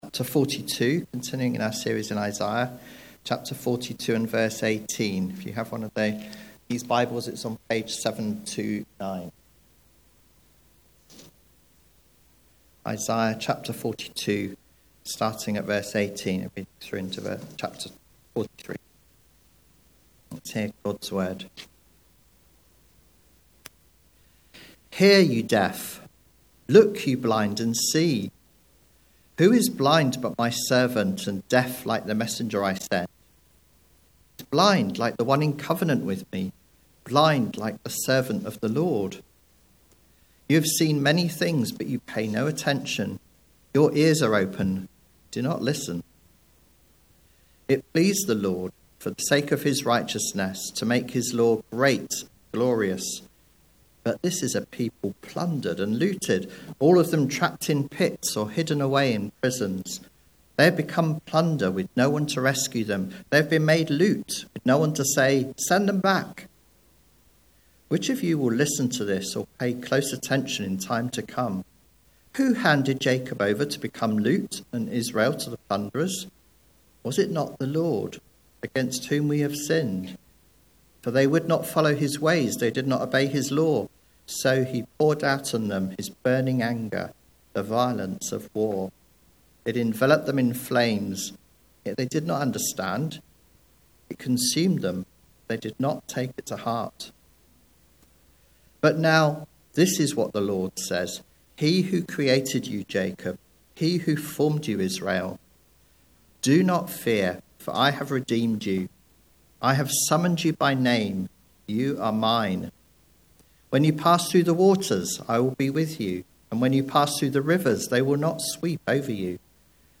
The God who makes all things new – Grace Church Kidlington